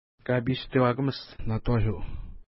Pronunciation: ka:pi:stewa:kəməst-na:twa:ʃu
Pronunciation